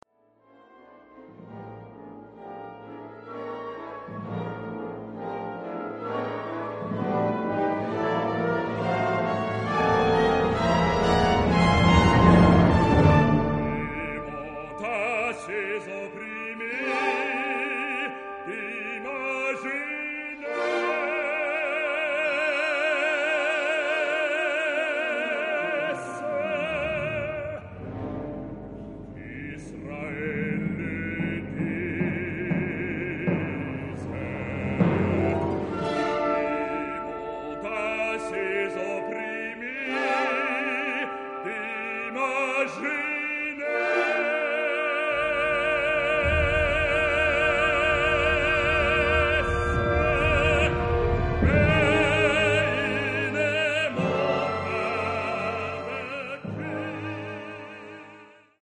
Género/Estilo/Forma: Sagrado ; Coro ; Salmo
Tipo de formación coral: SATB  (4 voces Coro mixto )
Solistas : Alto (1)  (1 solista(s) )
Instrumentación: Orquesta